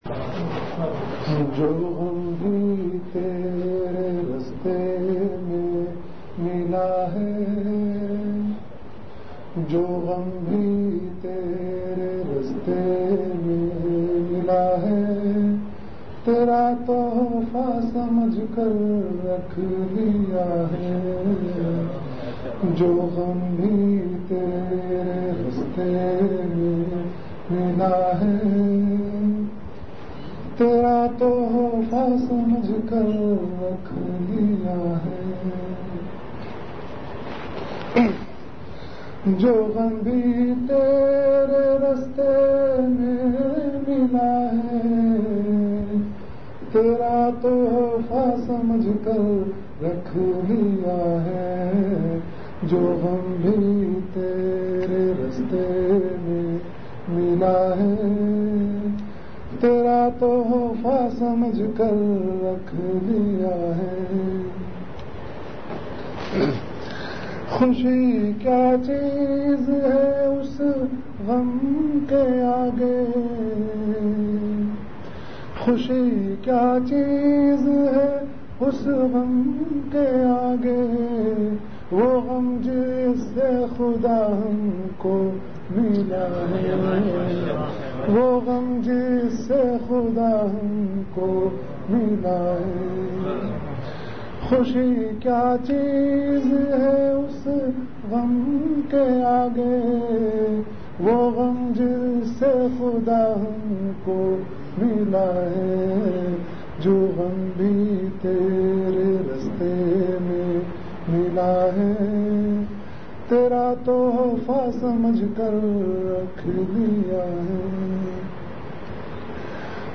Category Bayanat
Event / Time After Isha Prayer